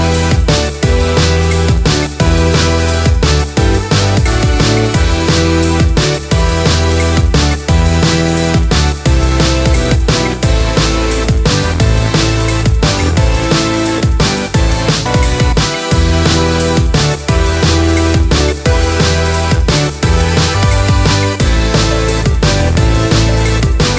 no Backing Vocals but with vocoder Dance 2:58 Buy £1.50